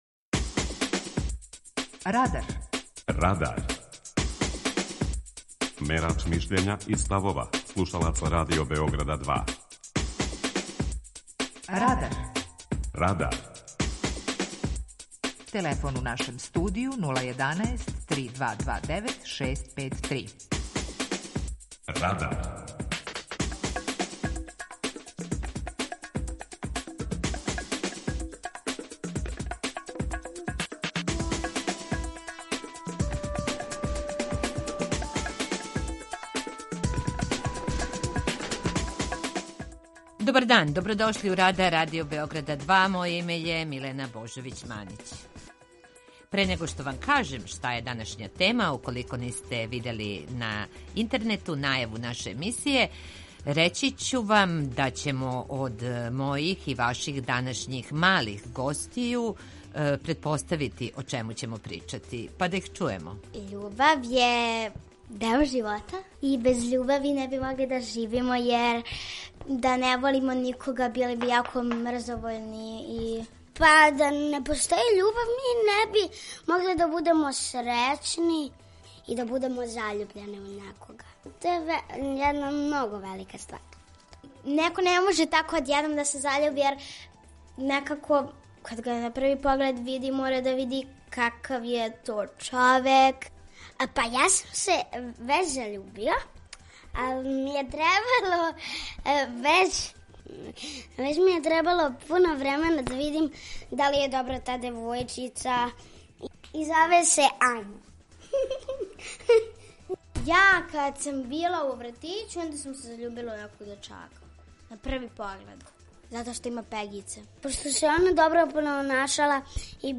Питање Радара је Да ли постоји љубав на први поглед? преузми : 18.84 MB Радар Autor: Група аутора У емисији „Радар", гости и слушаоци разговарају о актуелним темама из друштвеног и културног живота.